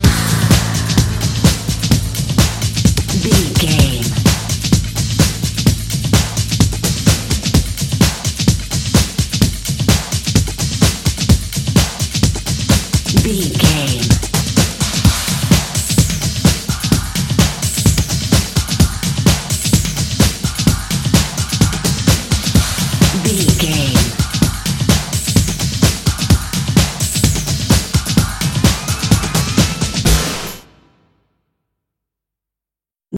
Aeolian/Minor
Fast
drum machine
synthesiser
electric piano
Eurodance